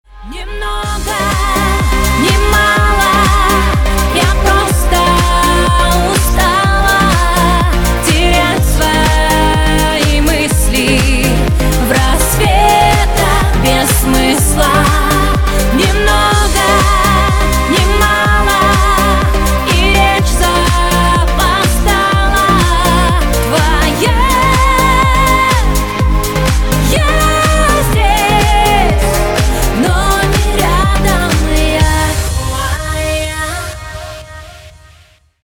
• Качество: 192, Stereo
поп
женский вокал
dance
Лиричная новинка